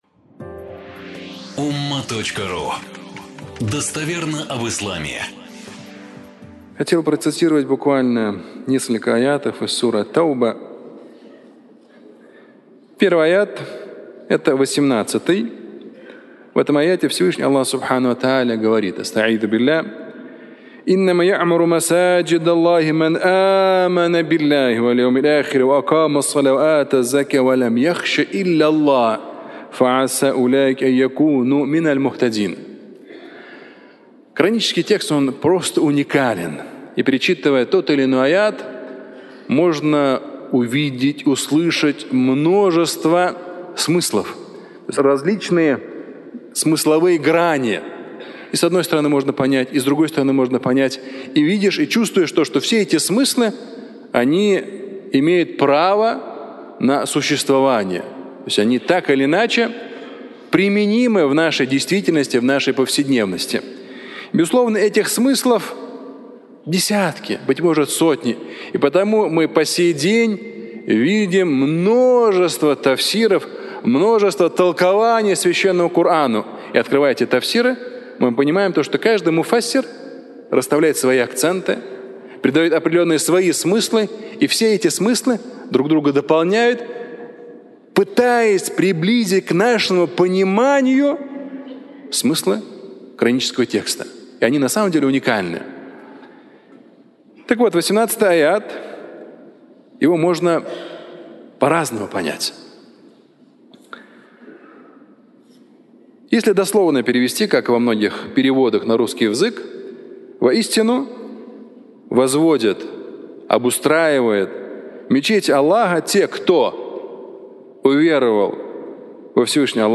Наш громоотвод (аудиолекция)